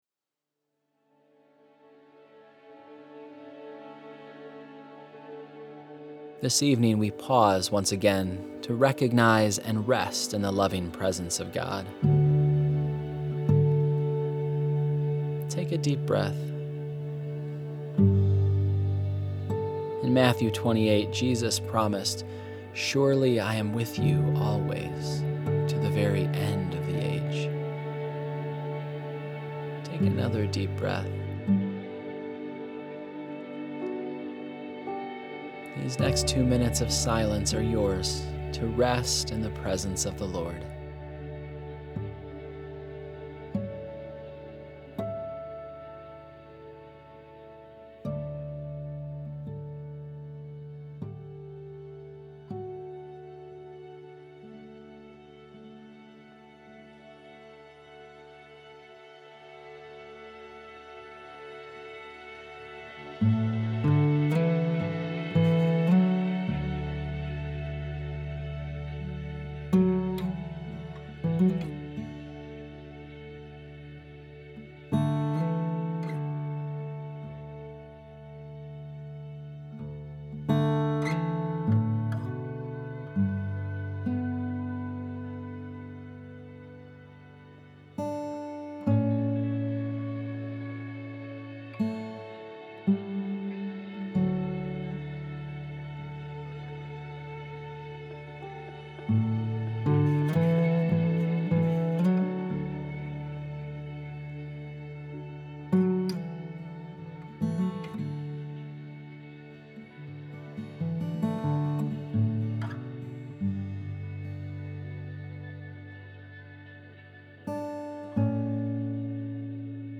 Below you will find a series of guided prayers.
Each prayer begins in silence.